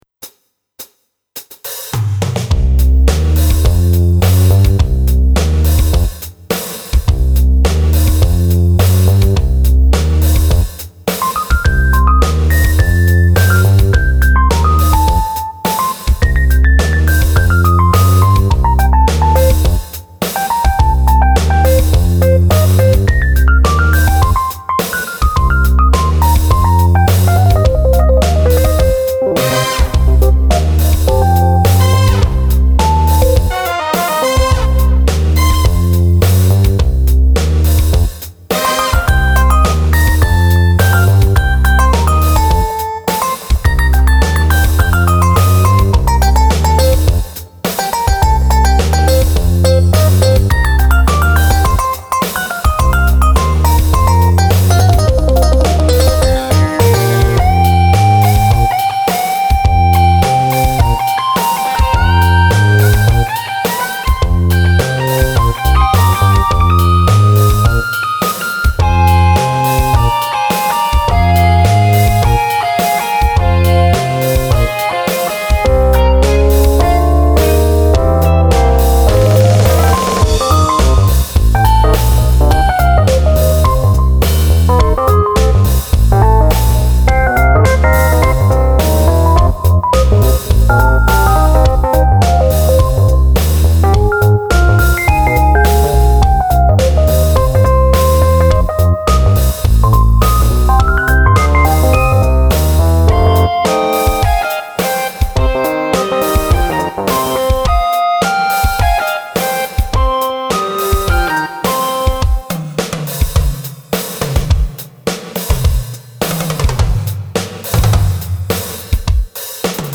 これもテーマができてから時間がかかった曲。ギターの絡みの遊びと、コード進行はいつもの大好きなパターン。